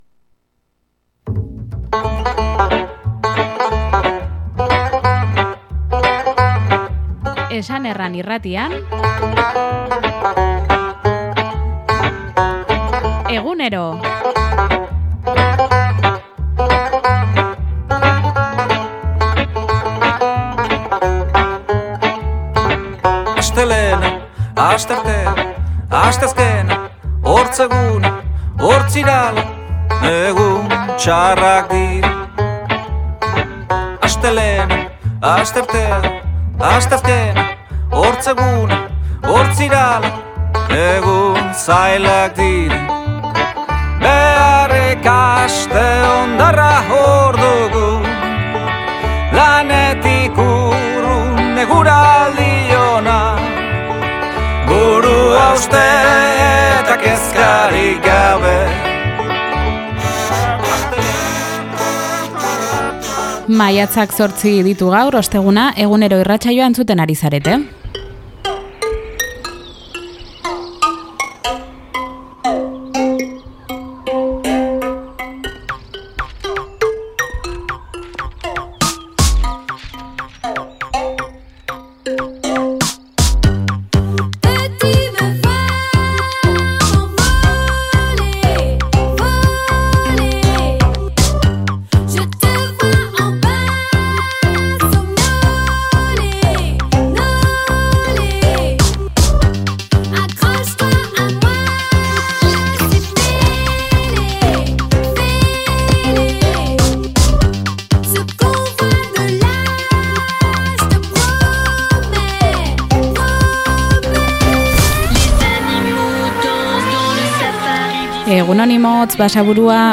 eskualdeko magazina